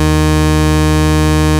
OSCAR 13 D#3.wav